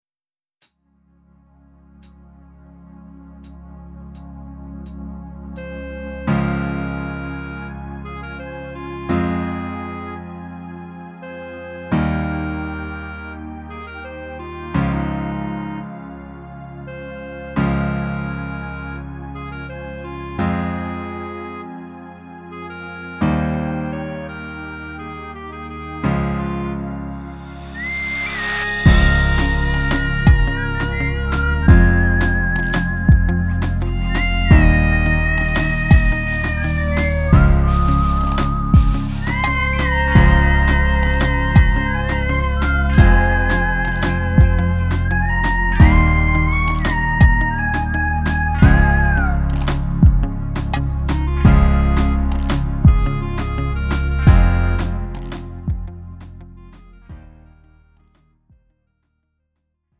음정 원키 4:03
장르 가요 구분 Lite MR